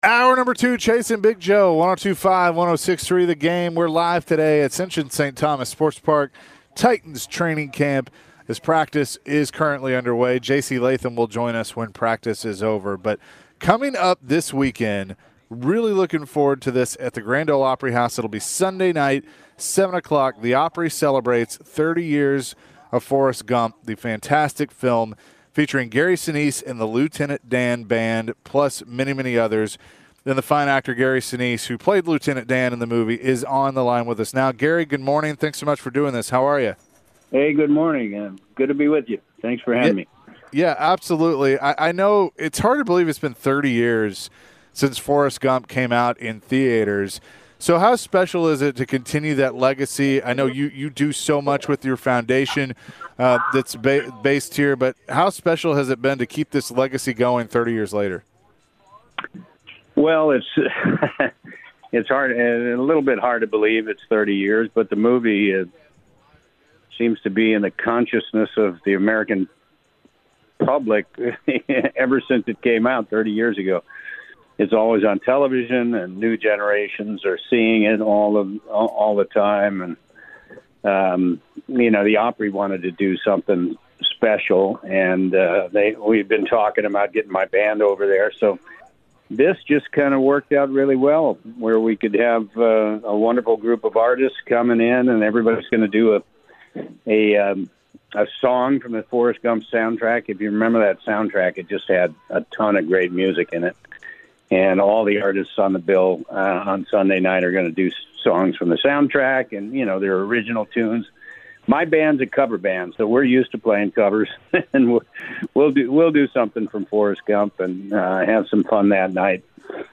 At the beginning of the second hour of the Chase & Big Joe Show, Gary Sinise otherwise known as Lieutenant Dan from the movie Forrest Gump joined the show.